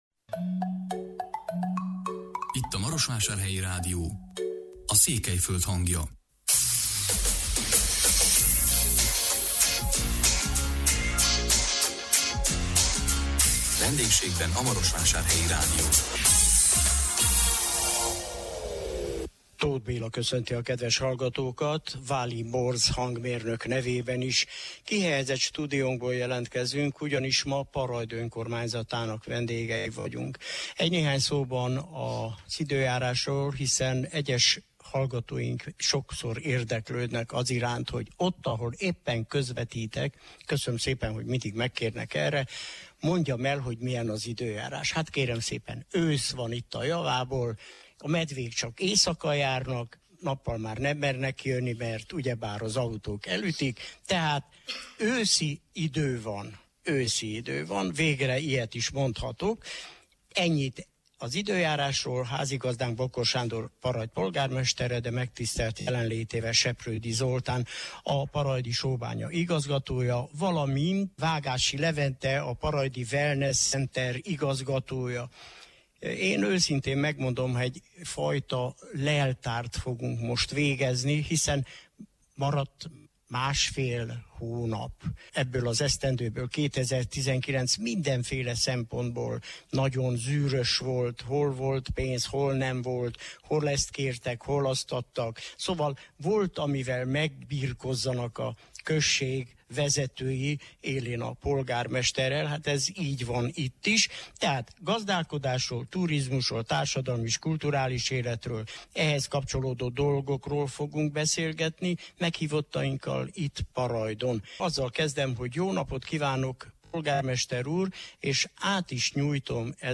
A 2019 november 21-én jelentkező műsorunkban a Hargita megyei Parajd vendégei voltunk. Meghívottainkkal a beruházásokról és a turizmus nyújtotta lehetőségekről beszélgettünk.